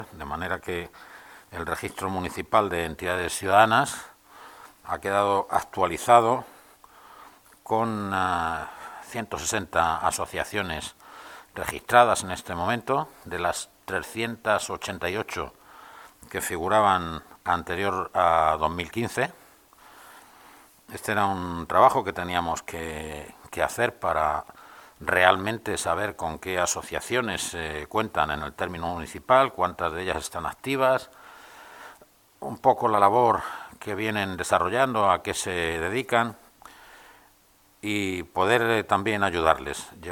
AUDIOS. Juan José Pérez del Pino, concejal de Participación Ciudadana